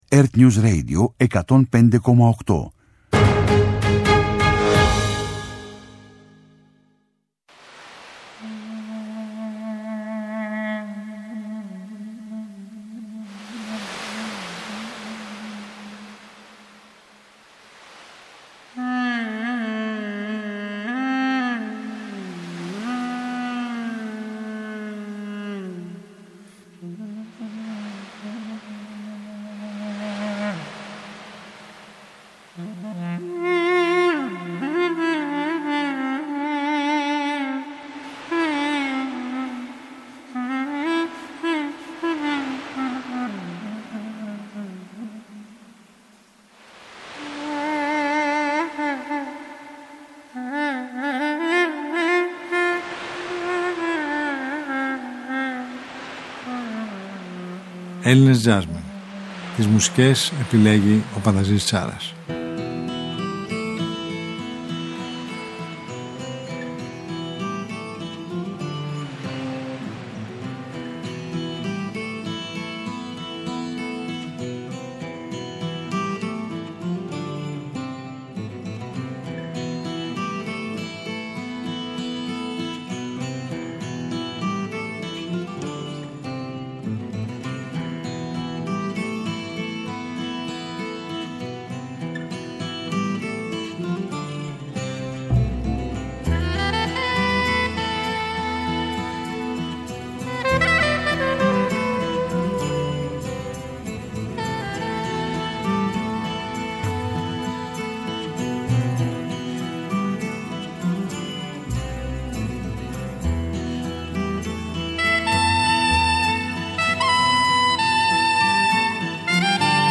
επιλογές από την ελληνική jazz σκηνή.